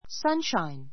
sunshine A1 sʌ́nʃain サ ンシャイン 名詞 （直射する） 日光 ; ひなた enjoy the sunshine enjoy the sunshine 日光を楽しむ, ひなたぼっこをする, 太陽の光を浴びる in the sunshine in the sunshine ひなたで[に] After rain comes sunshine.